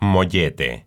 moh-YAY-tay), native to Mexico City, is a bolillo sliced lengthwise and partially hollowed.